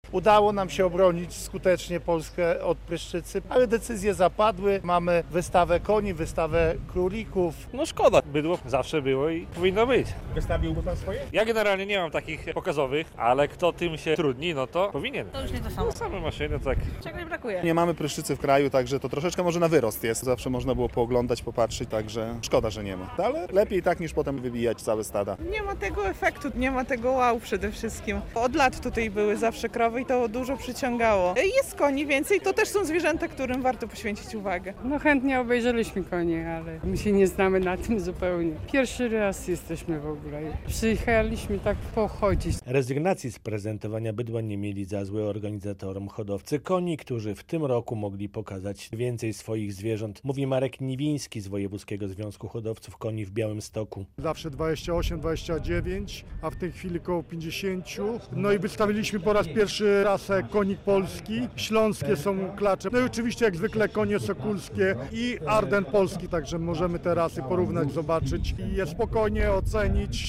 Rolniczy weekend w Szepietowie. Bez bydła, ale z bogatą ofertą wystawienniczą - relacja